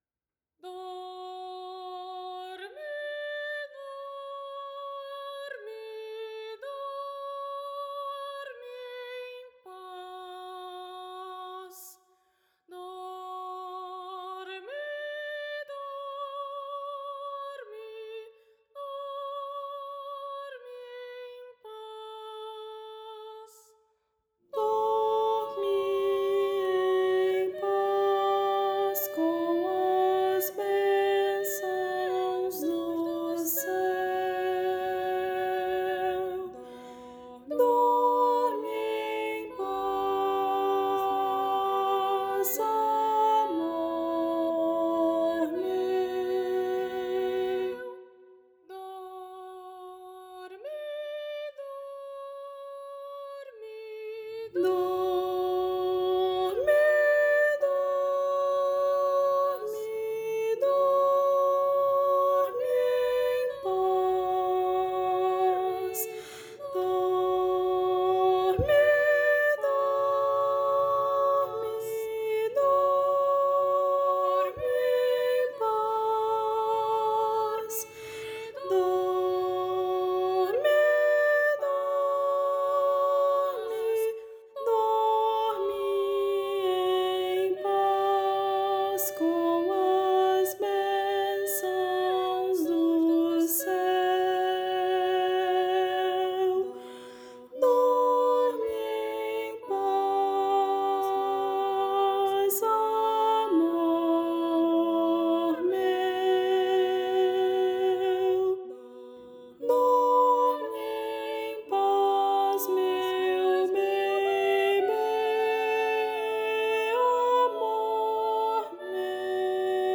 para coro infantil a três vozes
uma singela canção de ninar
Voz Guia 2